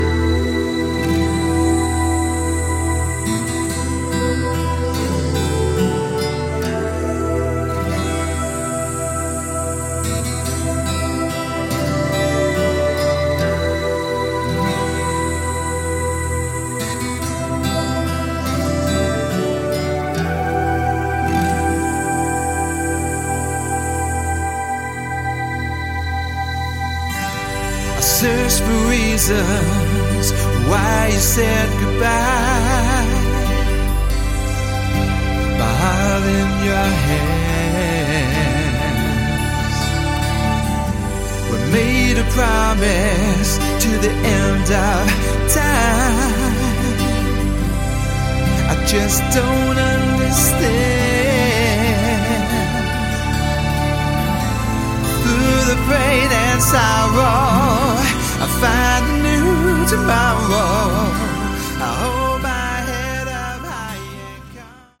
Category: AOR
lead and backing vocals, guitar, bass, drums